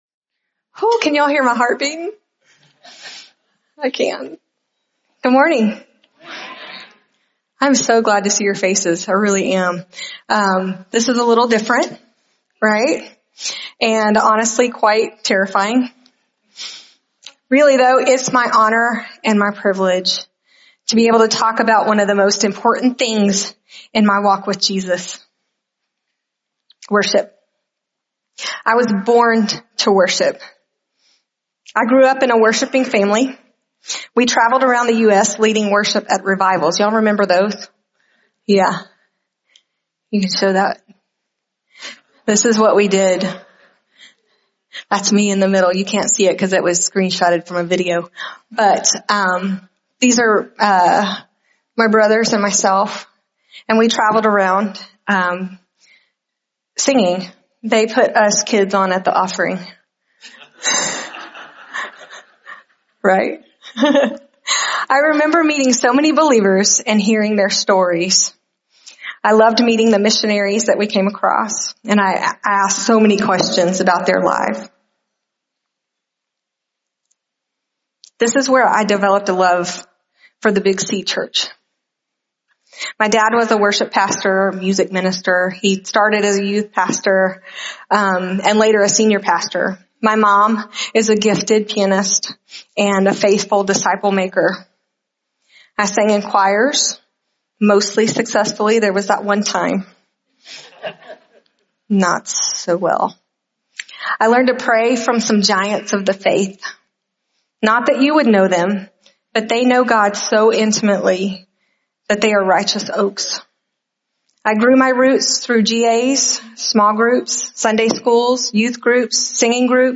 This sermon reminds us that worship is at the heart of who we are—it’s what we were created to do. From the Levites in the Old Testament to the call for true worshipers in John’s gospel, the message highlights how worship connects us with God and reveals His presence in our lives.